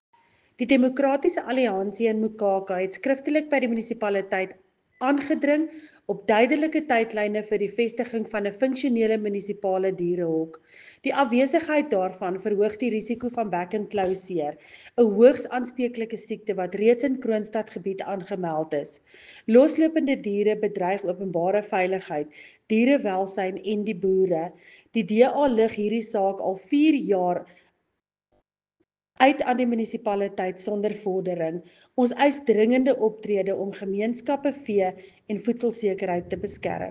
Afrikaans soundbites by Cllr Linda Louwrens and